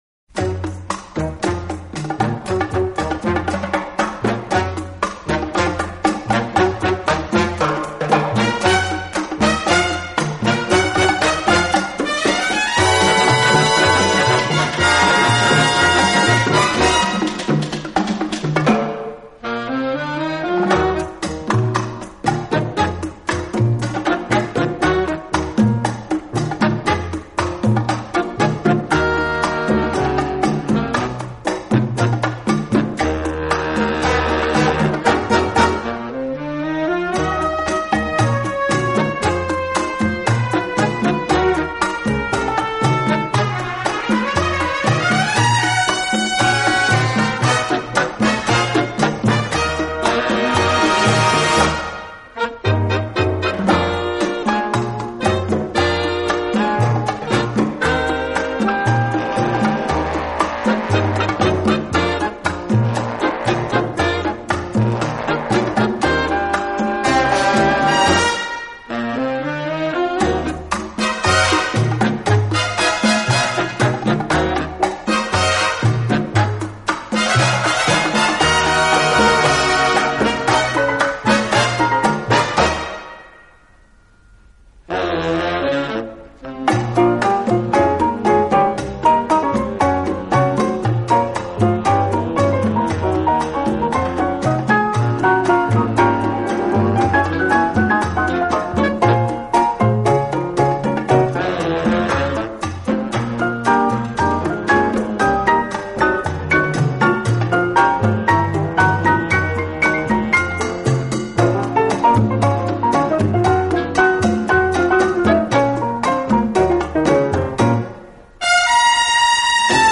【探戈纯音乐】
节奏更适合于跳舞。
曲子开头通常伴有许多手风琴，这使得音乐在某种程度上稍具古风。